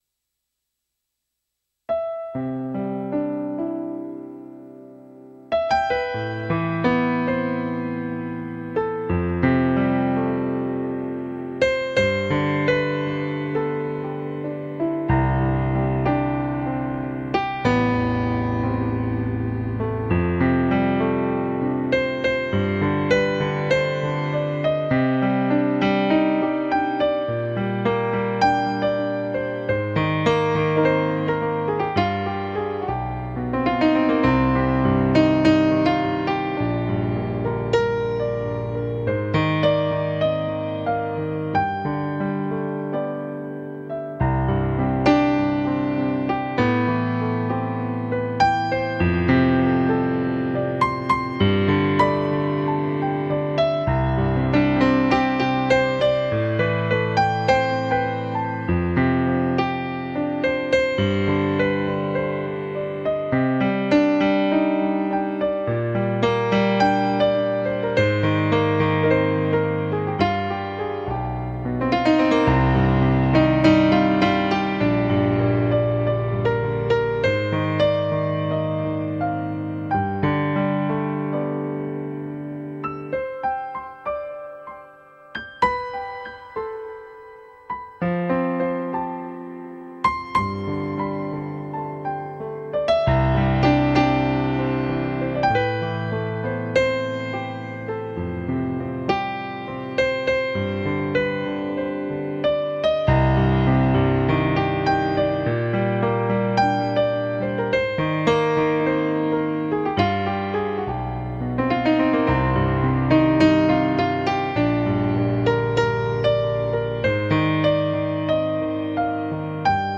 Lyrical piano music to lift your spirits.